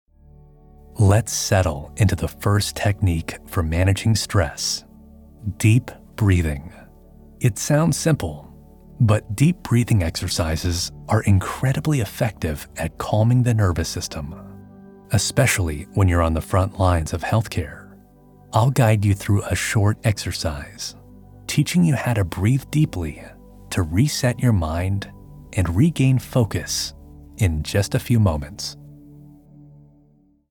NARRATION
A patient and empathetic guide who helps listeners manage stress with calm and simple techniques, creating a sense of peace and focus in high-pressure environments.